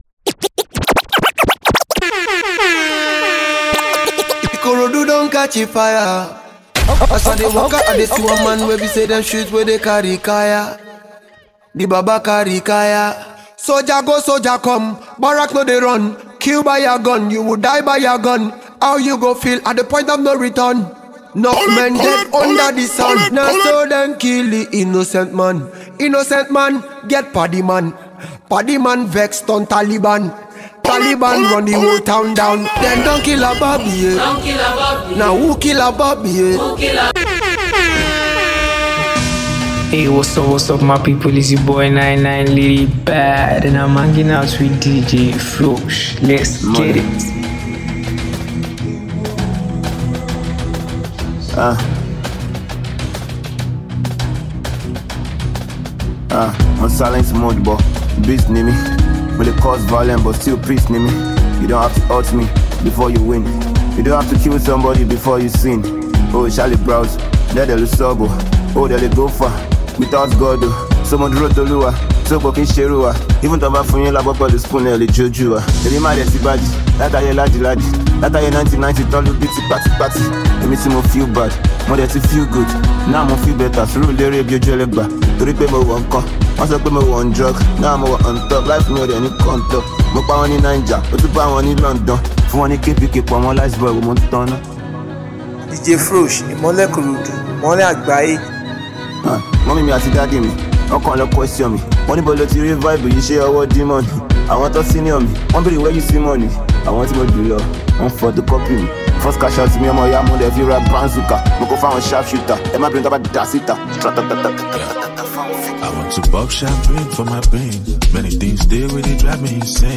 Check out the hot new mix